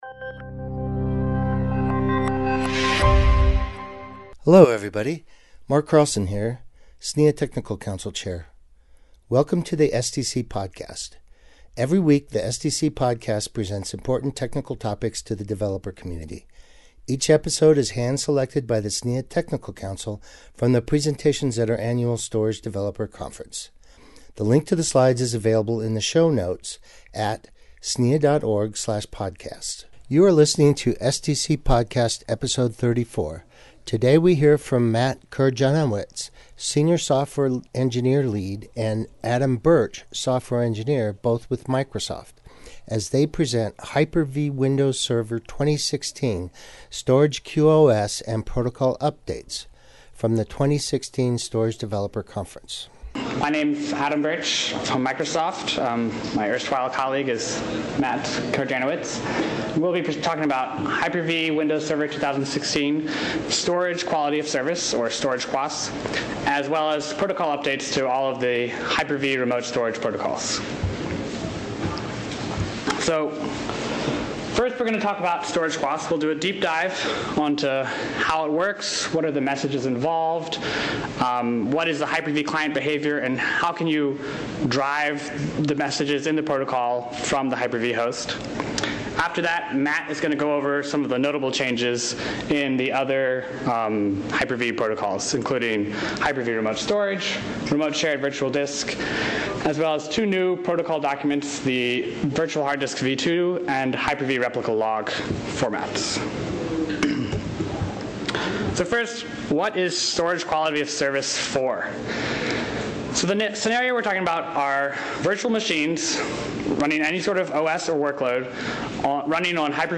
Podcast Presentation